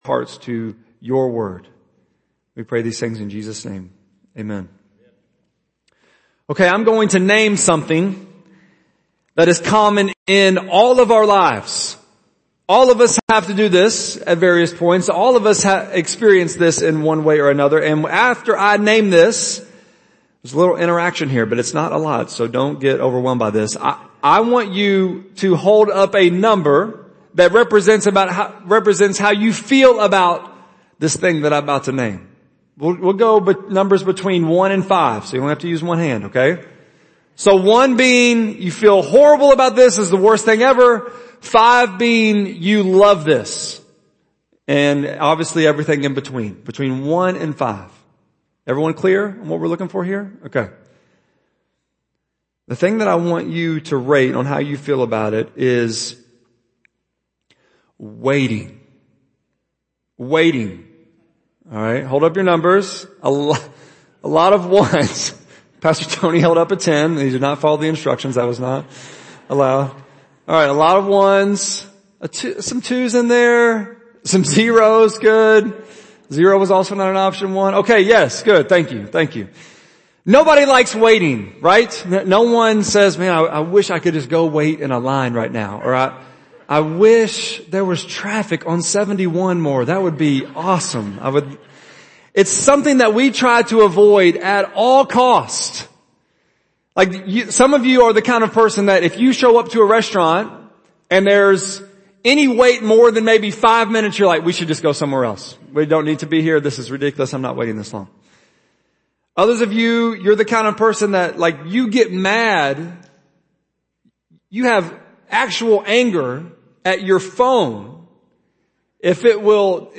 6.27-sermon.mp3